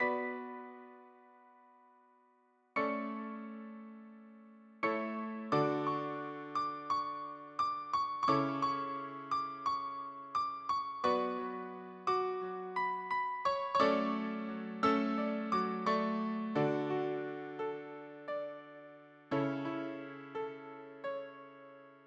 piano.mp3